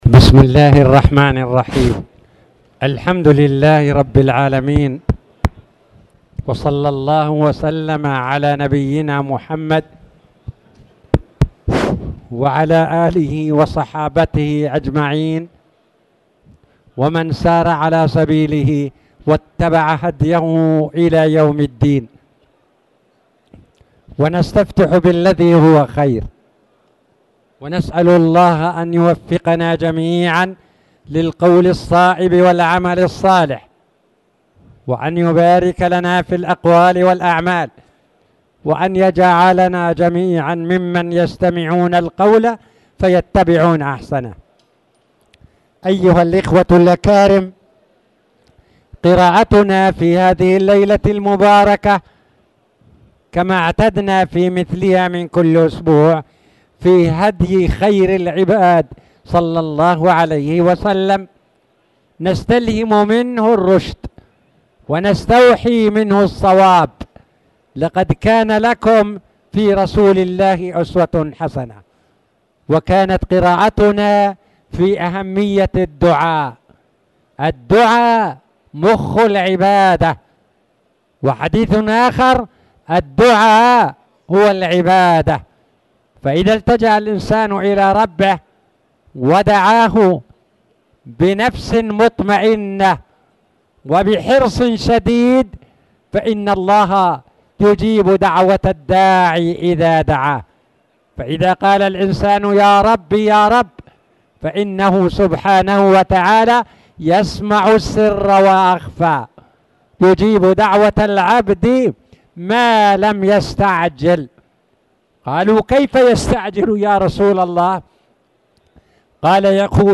تاريخ النشر ٢٤ جمادى الآخرة ١٤٣٨ هـ المكان: المسجد الحرام الشيخ